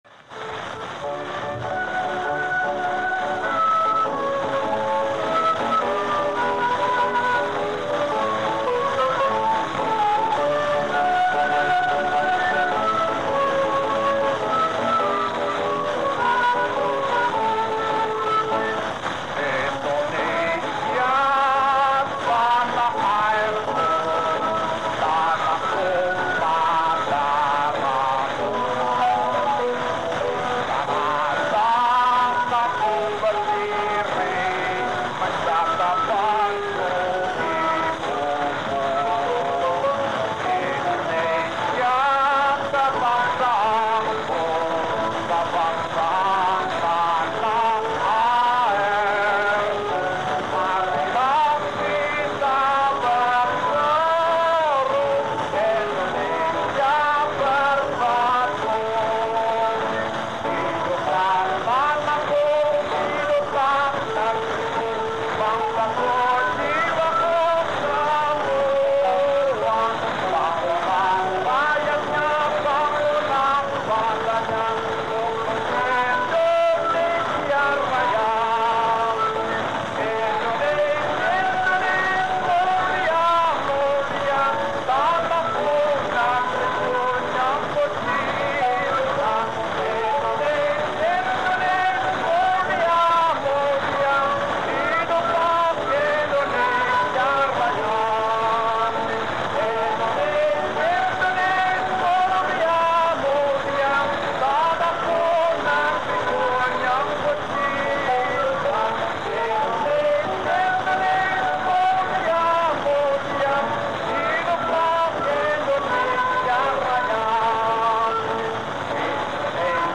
Ретро запись
со словами
виниловая пластинка